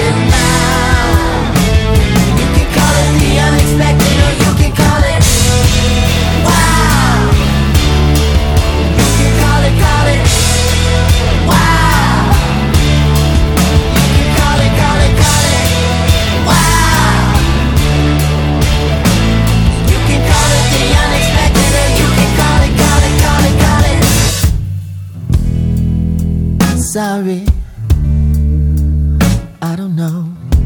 "templateExpression" => "Rhythm'n'blues, soul"